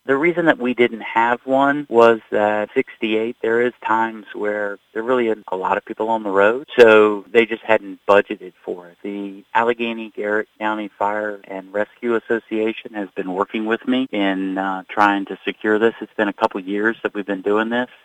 SHA CHART trucks will now patrol Interstate 68 Friday through Sunday as well as holiday Mondays through October 5. Senator Mike McKay said the trucks are used for traffic incident management and to protect first responders, something the rest of the state had, but not Allegany and Garrett Counties…